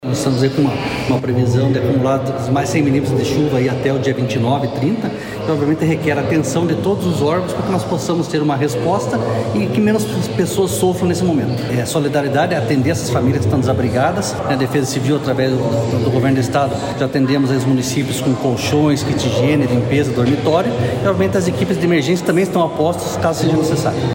Sonora do coordenador estadual da Defesa Civil, coronel Fernando Schunig, sobre a atuação dos profissionais contratados para o Verão Maior Paraná no auxílio à população afetada pelas chuvas